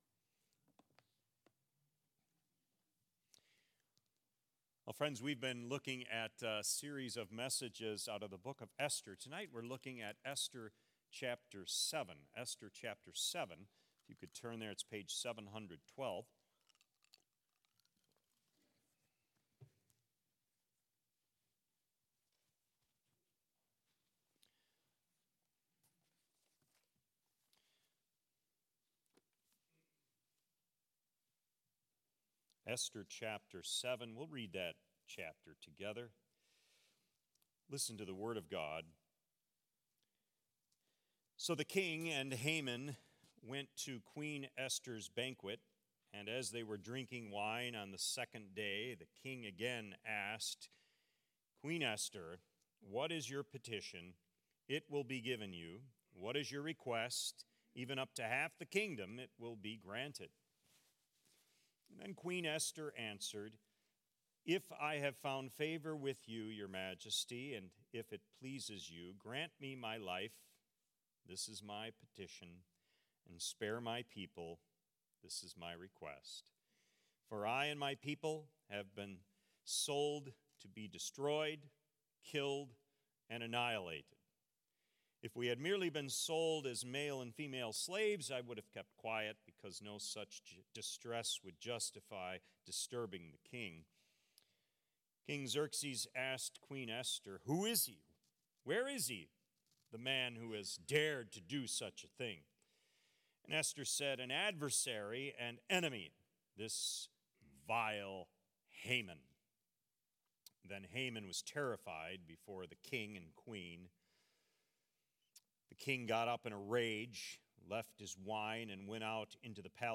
Sermons | Faith Community Christian Reformed Church